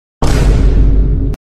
Boom Effect